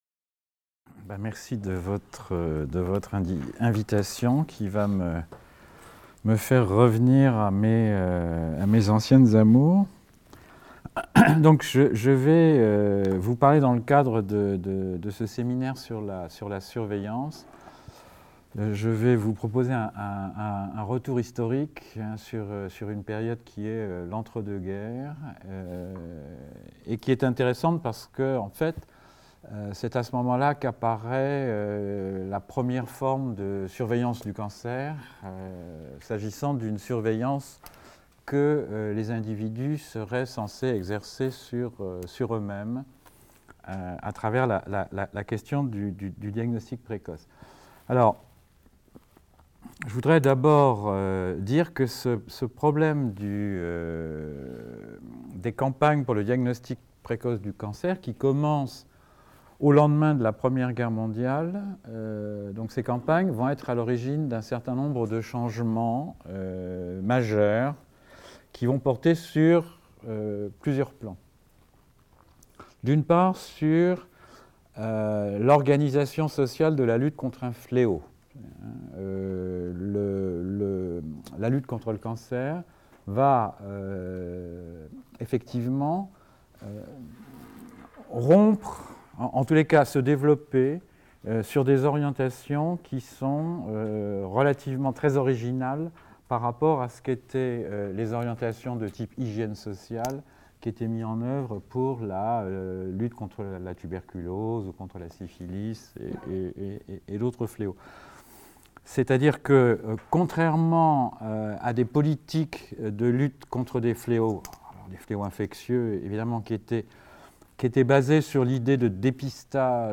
Intervention au séminaire formes de surveillance en médecine et santé publique.